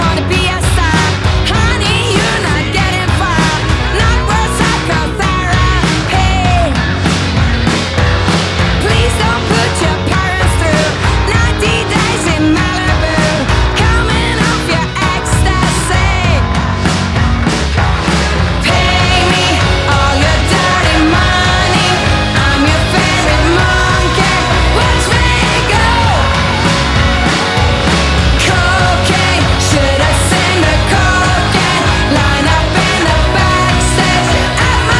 Жанр: Рок / Альтернатива
Indie Rock, Alternative